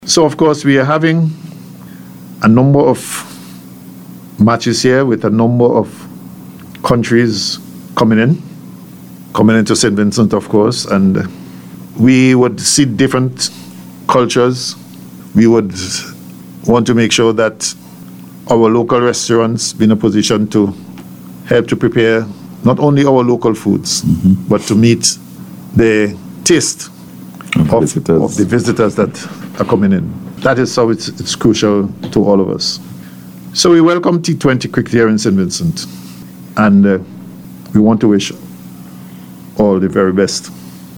Daniel was speaking on NBC’s Face to Face programme, Wednesday.